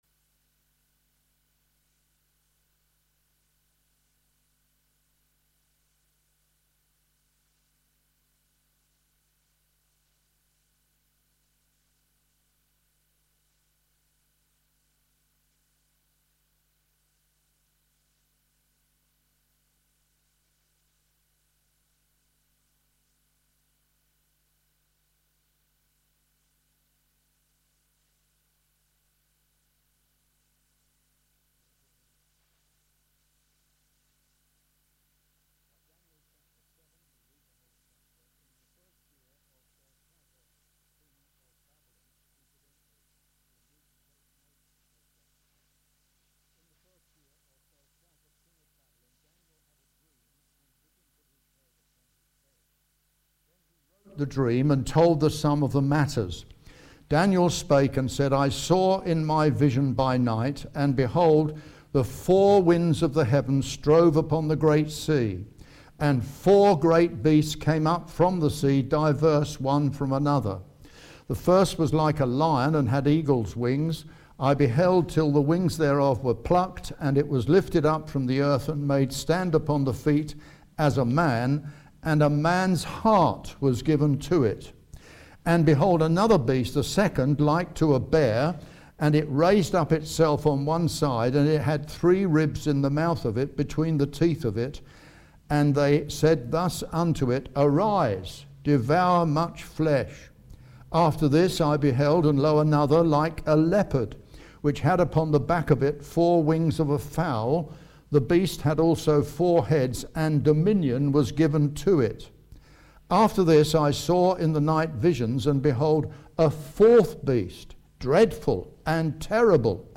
Unfortunately, the final minutes of the meeting were lost from the recording due to a technical issue for which we apologize.